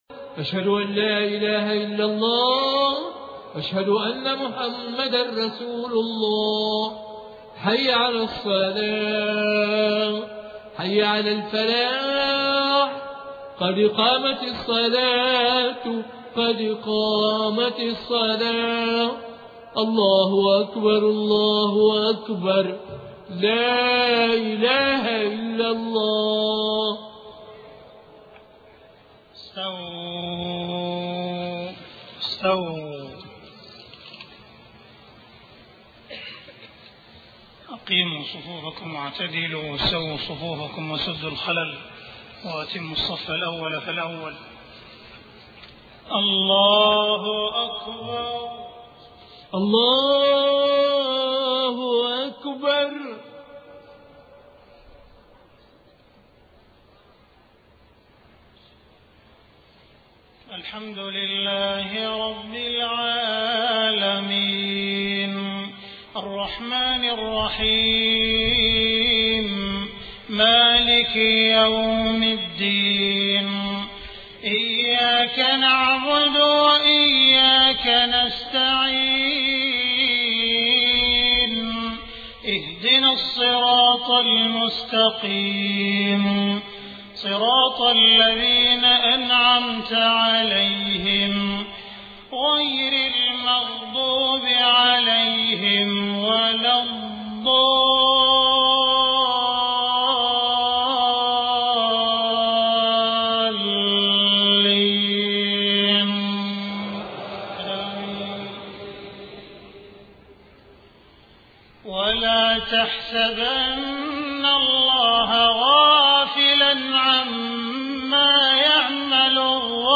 صلاة المغرب 15 محرم 1429هـ خواتيم سورة إبراهيم 42-52 > 1429 🕋 > الفروض - تلاوات الحرمين